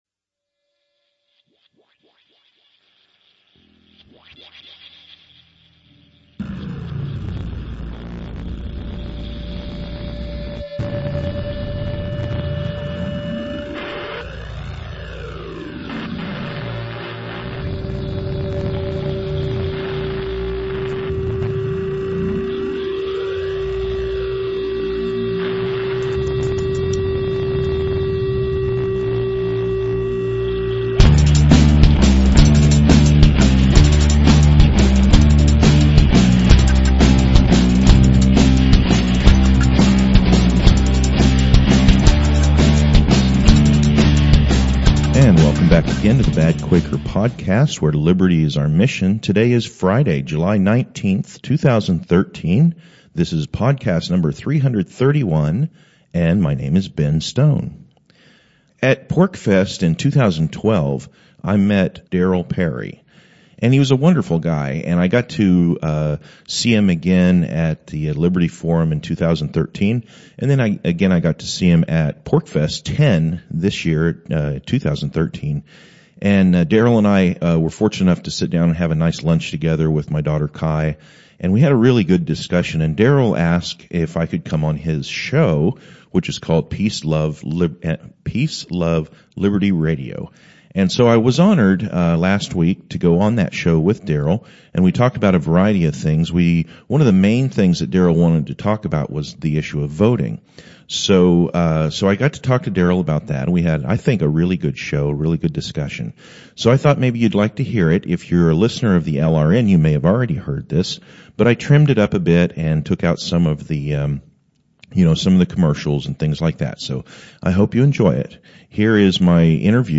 A discussion about Liberty, Revolution, Austrian Economics, and Anarcho-Capitalism.